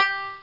Banjosoft Sound Effect
banjosoft.mp3